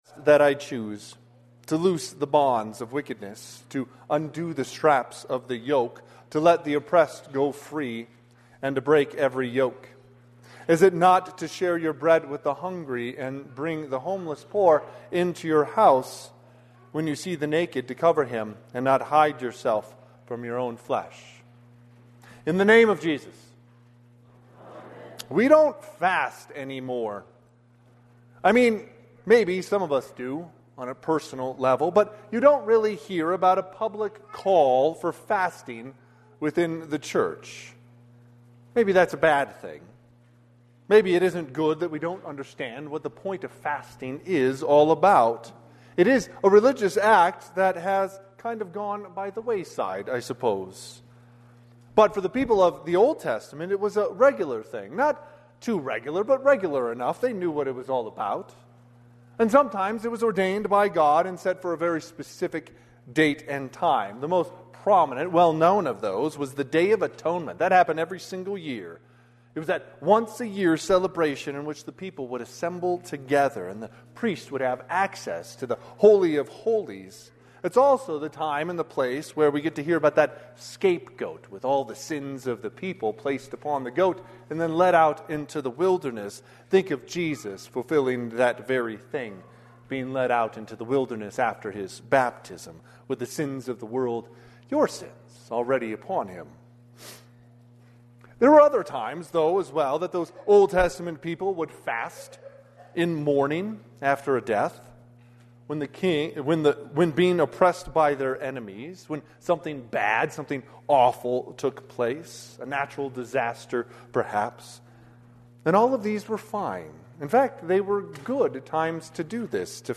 Sermon - 2/8/2026 - Wheat Ridge Evangelical Lutheran Church, Wheat Ridge, Colorado
Fifth Sunday after Epiphany